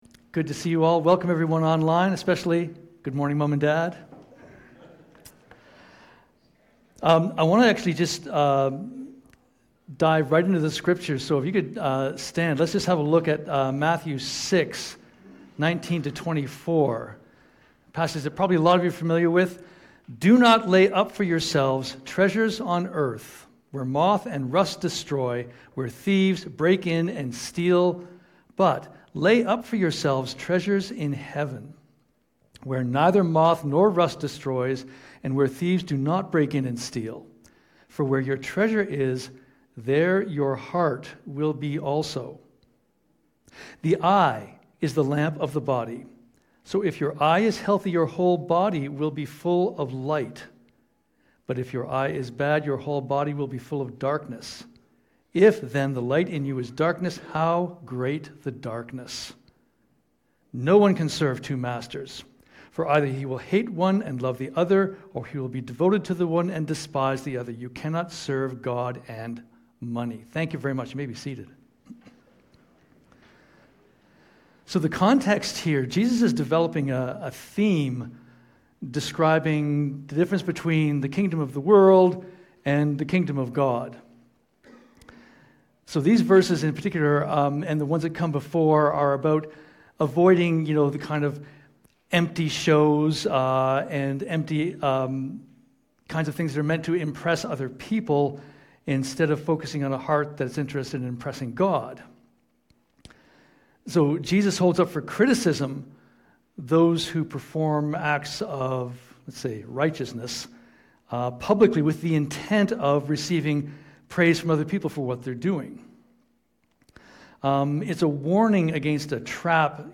Sermon Audio and Video You Can't Take it with You!